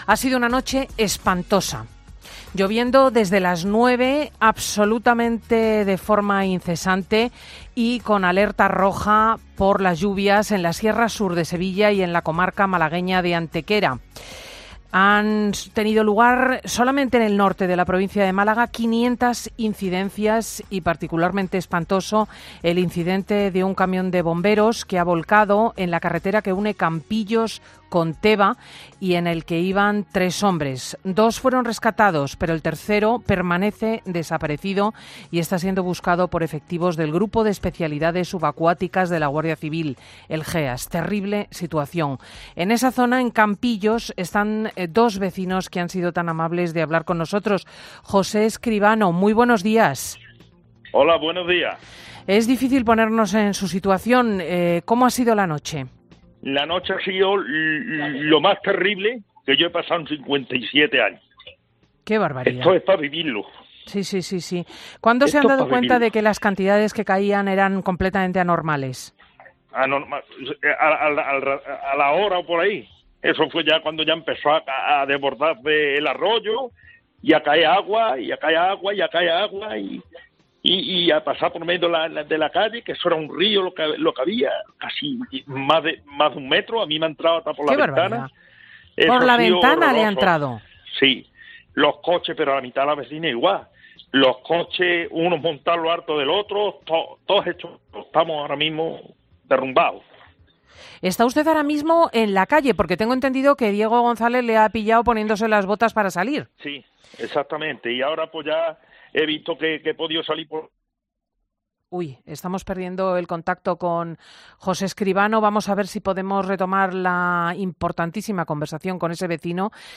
Vecinos de Campillos relatan la terrible noche vivida por las fuertes lluvias